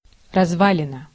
Ääntäminen
IPA: /ˈpœy̯nˌɦoːp/